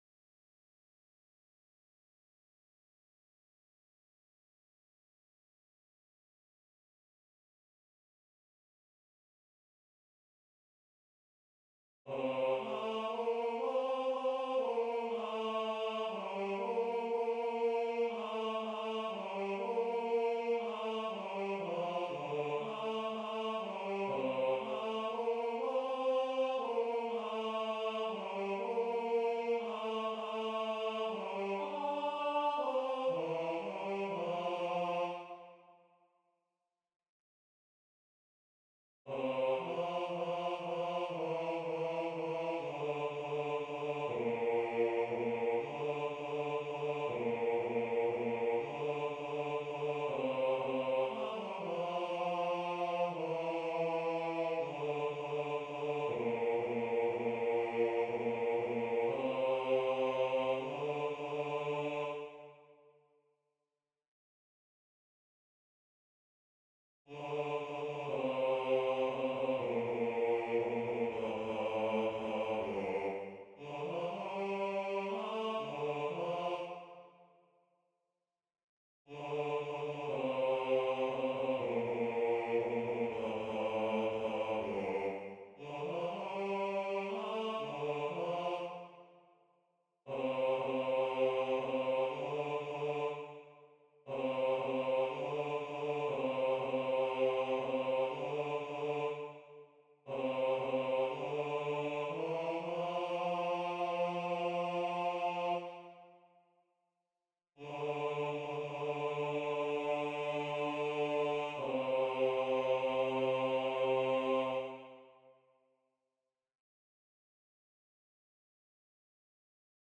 The Lord Is My Shepherd (Psalm_23)-Bass2.mp3